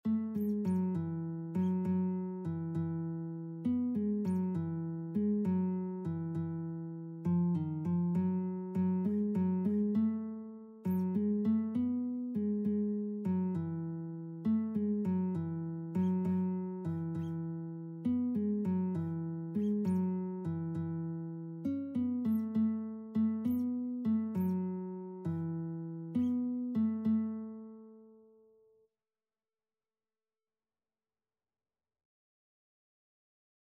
6/8 (View more 6/8 Music)
A major (Sounding Pitch) (View more A major Music for Lead Sheets )
Classical (View more Classical Lead Sheets Music)